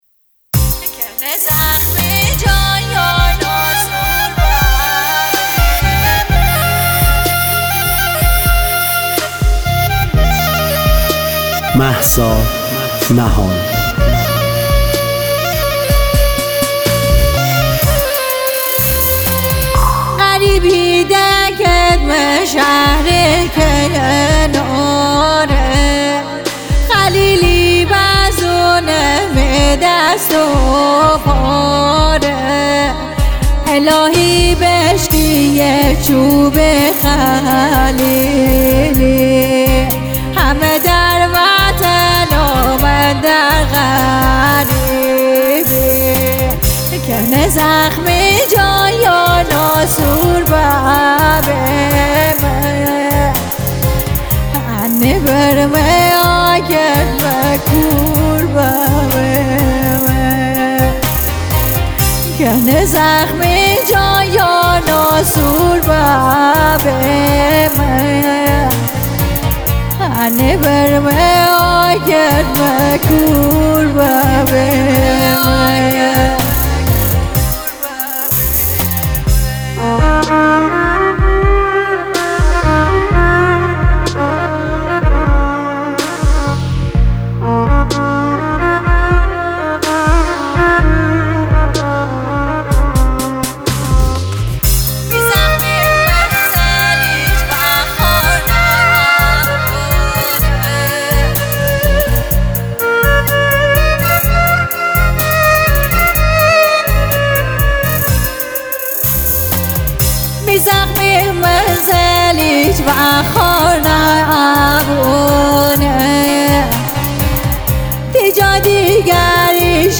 غمگین
دانلود آهنگ غمگین مازندرانی